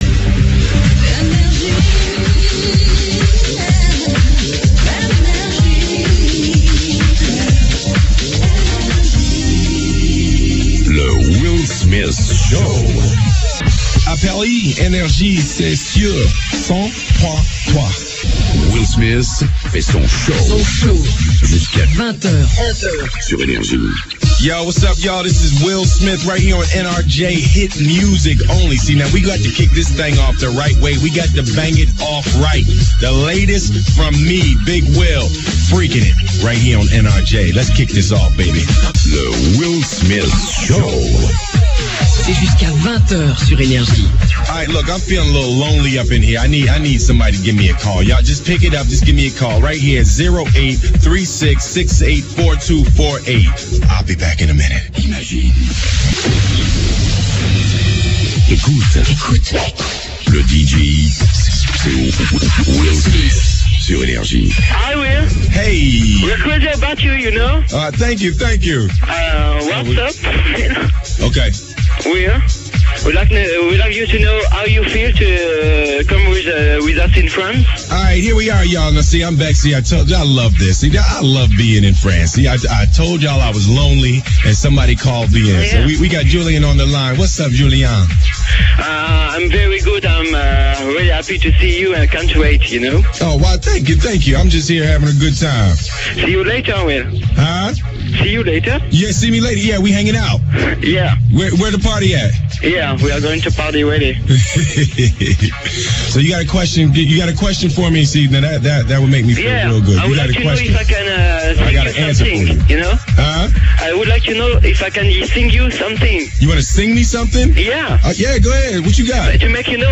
A cool gag from April 1st, 2000: "Le Will Smith Show" live at NRJ Paris